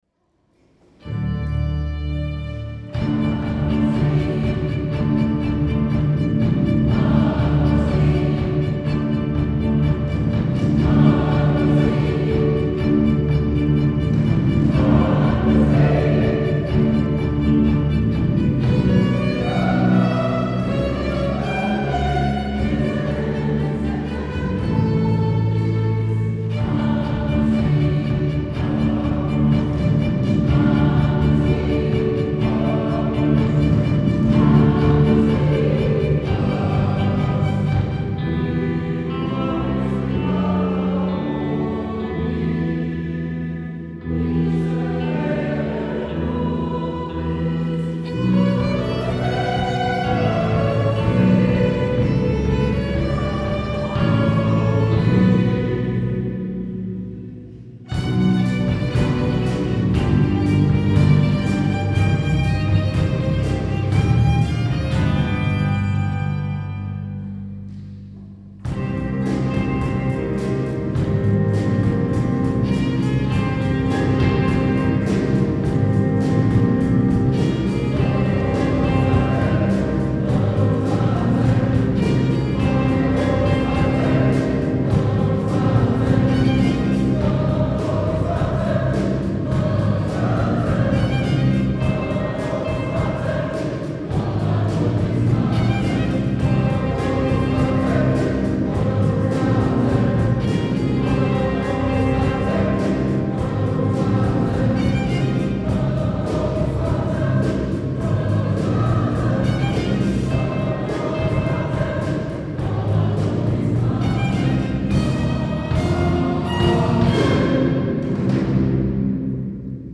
Gattung: Messe